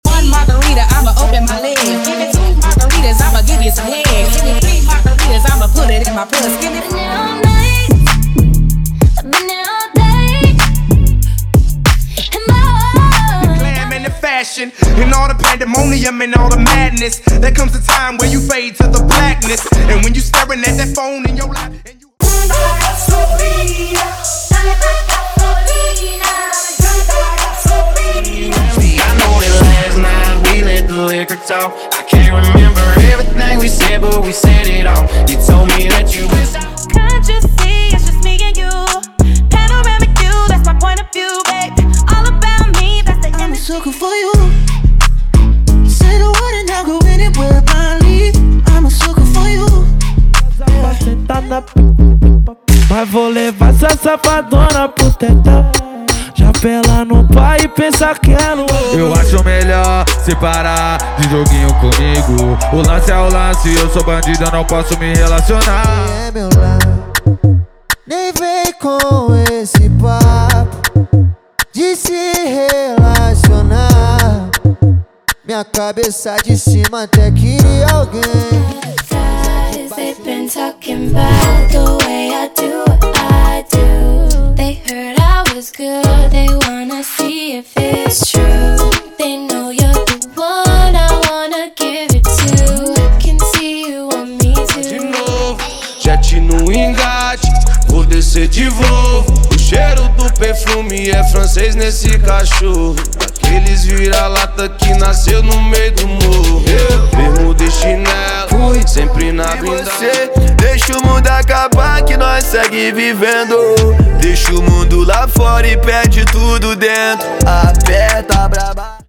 Pack Trap & Rap
Os Melhores Remix do momento estão aqui.
– Sem Vinhetas